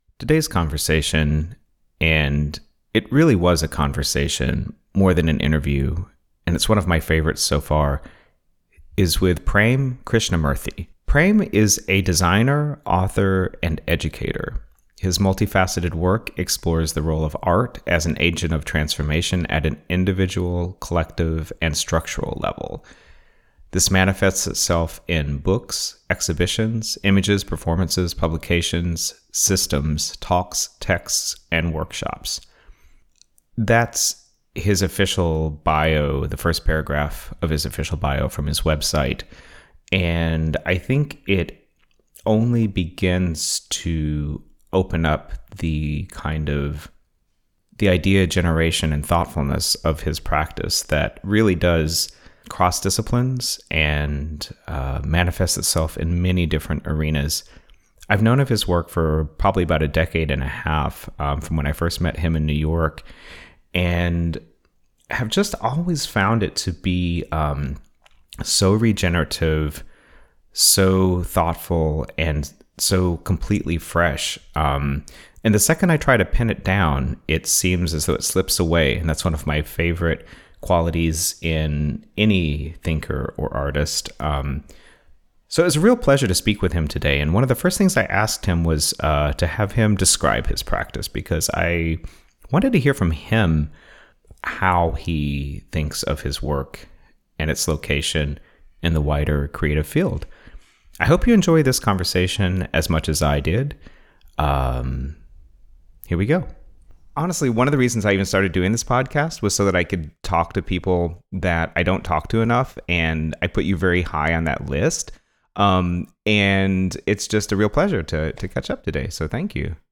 A conversation with artist